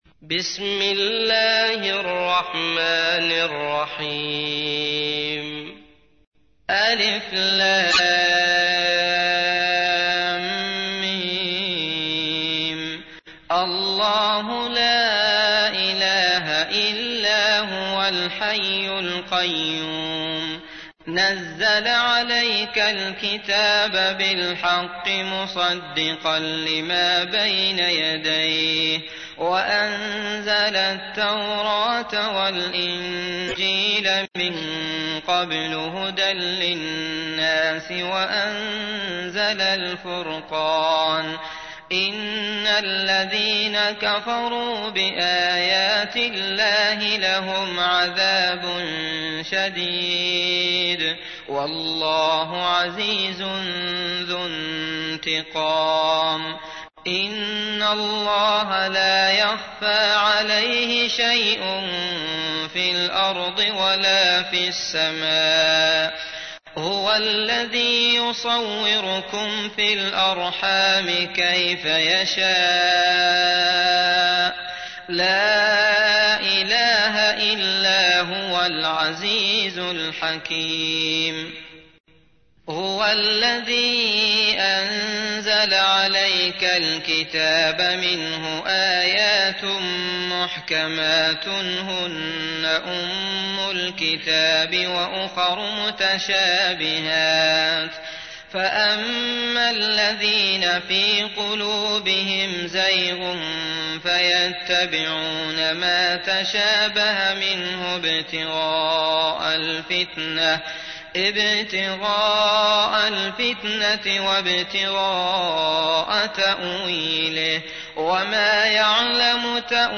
تحميل : 3. سورة آل عمران / القارئ عبد الله المطرود / القرآن الكريم / موقع يا حسين